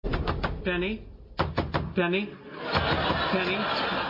knock knock knock penny sound effect
knock-knock-knock-penny.mp3